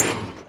Sound / Minecraft / mob / blaze / hit4.ogg
hit4.ogg